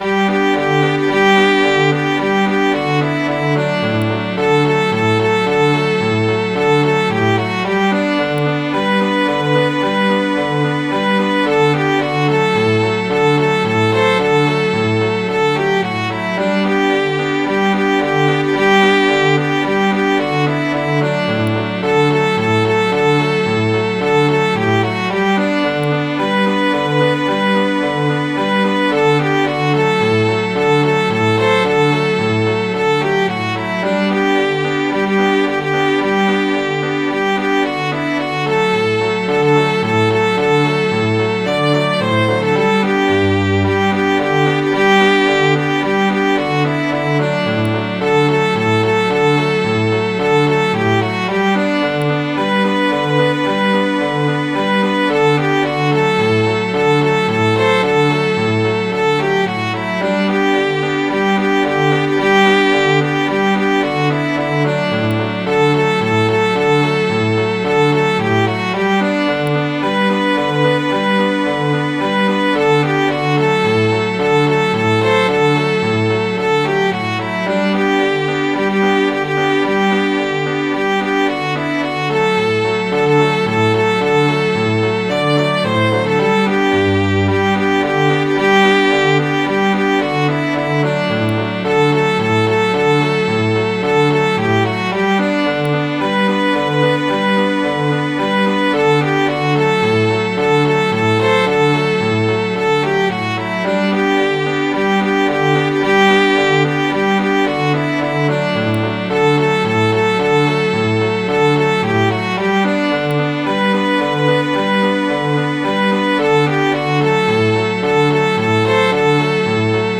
Midi File, Lyrics and Information to The Patriotic Diggers